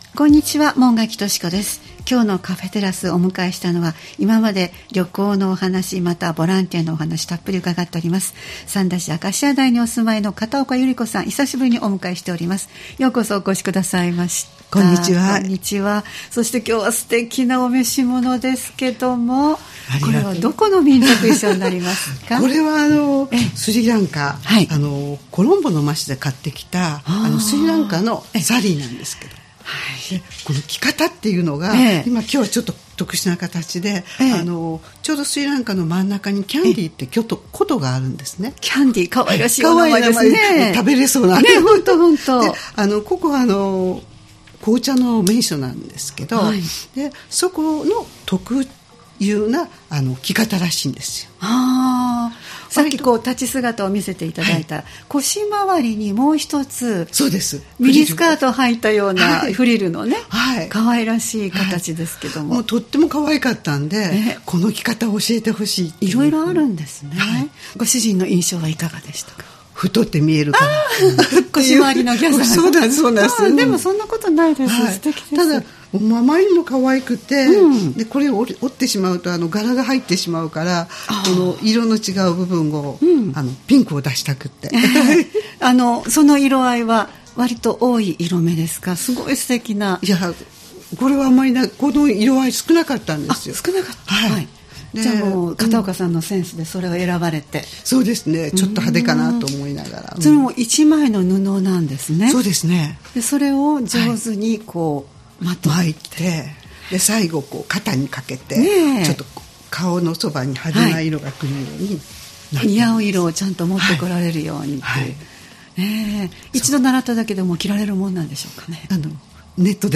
様々なゲストをお迎えするトーク番組「カフェテラス」（再生ボタン▶を押すと放送が始まります）